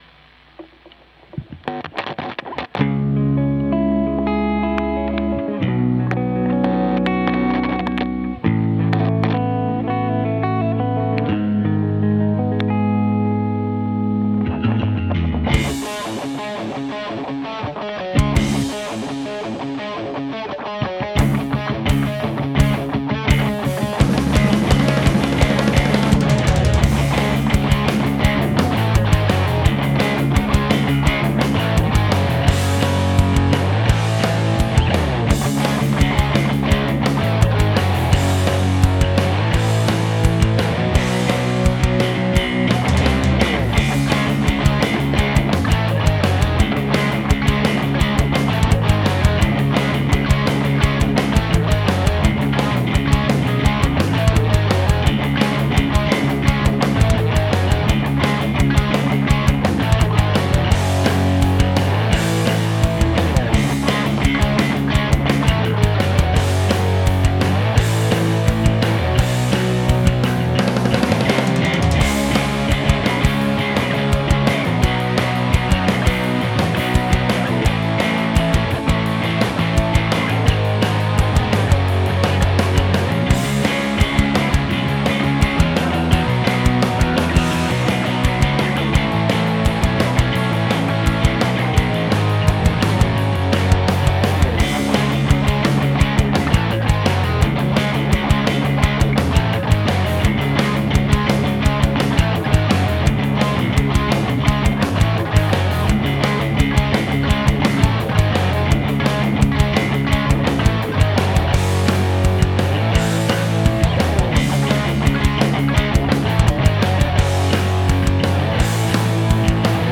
need to retrack arabic solo since the ending goes a bit out of time